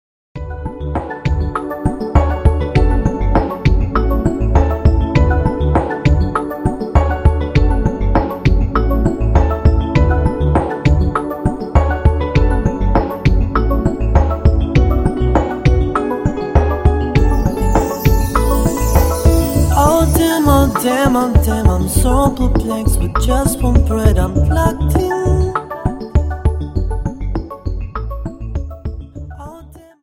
Dance: Rumba 25 Song